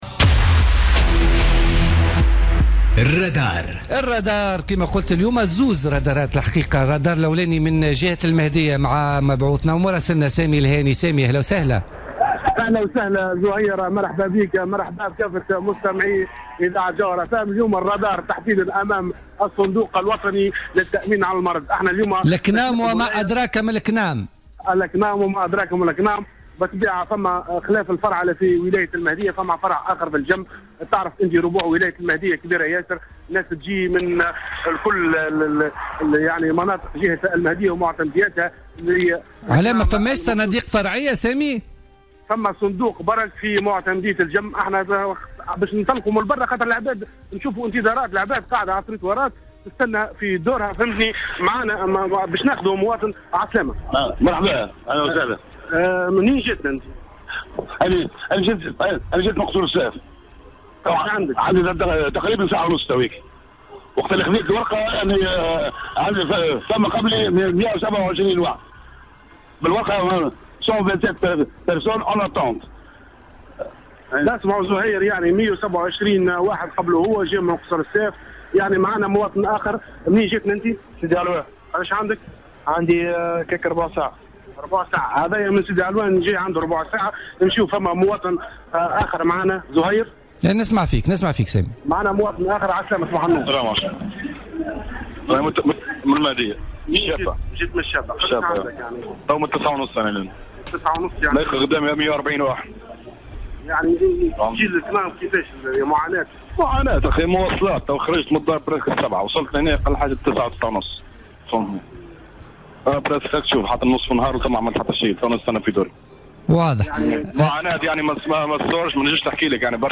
حل فريق فقرة "الرادار" اليوم الاثنين 3 أكتوبر 2016 بمقر فرع الصندوق الوطني للتأمين على المرض بمدينة المهدية.